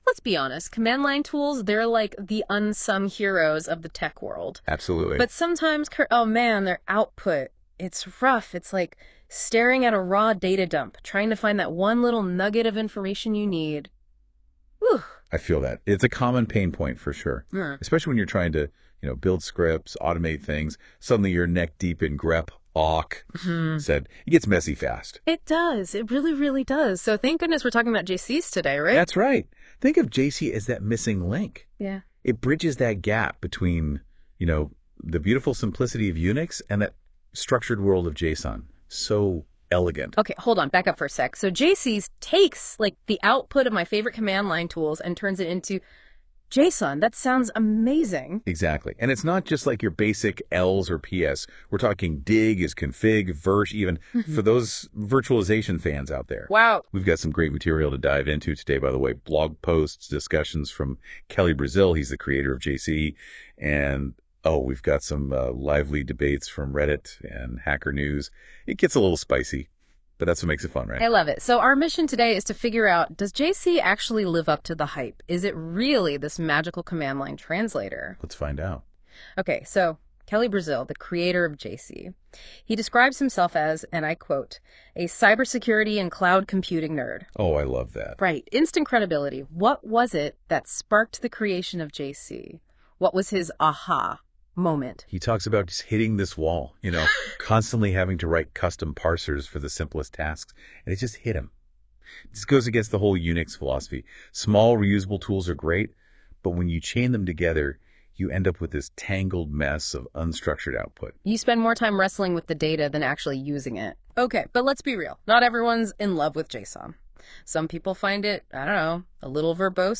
I was having a little fun with NotebookLM and it generated this cool "podcast" which provides a deep dive on JC, what it does, and the pros and cons.